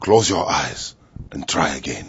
zen_closeureyesntryagain.wav